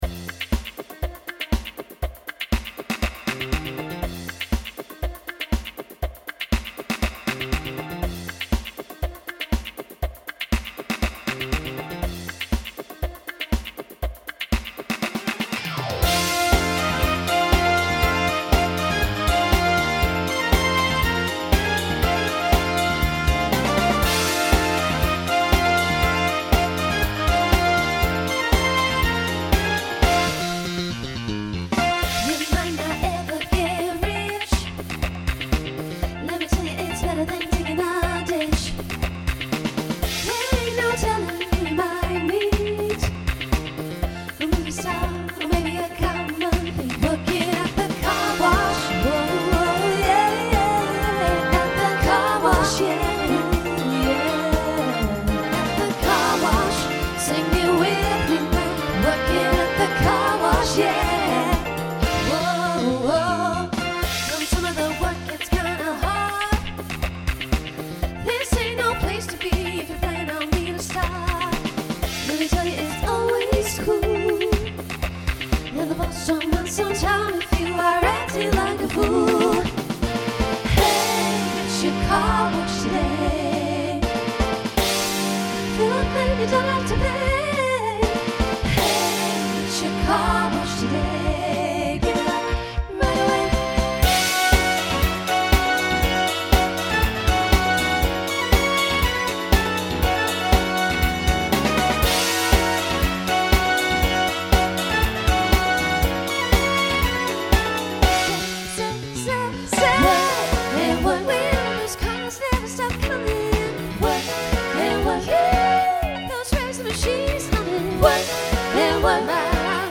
Voicing SSA Instrumental combo Genre Disco , Pop/Dance